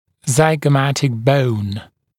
[ˌzaɪgə’mætɪk bəun][ˌзайгэ’мэтик боун]скуловая кость